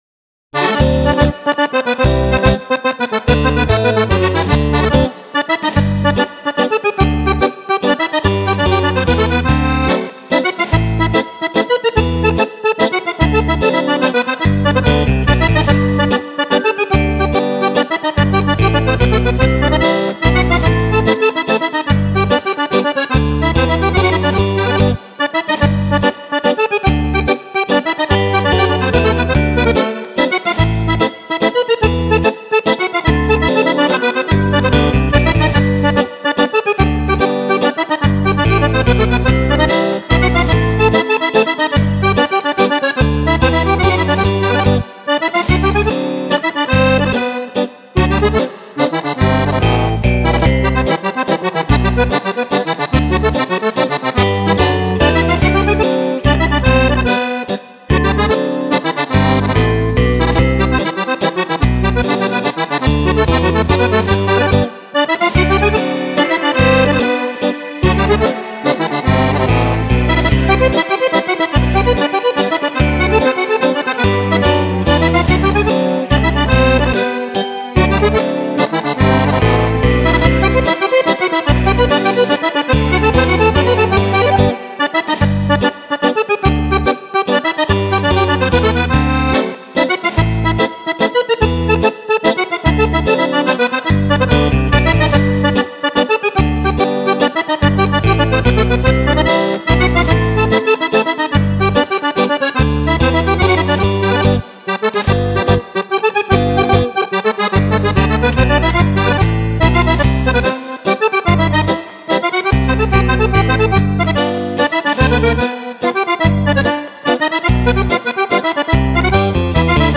Mazurka
10 ballabili per Fisarmonica
Fisarmonica
Chitarre